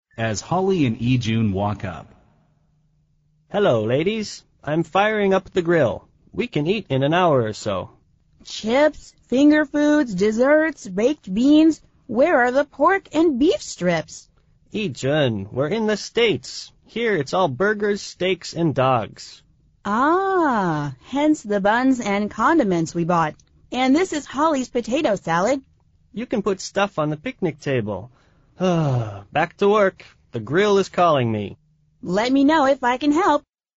美语会话实录第201期(MP3+文本):Fire up!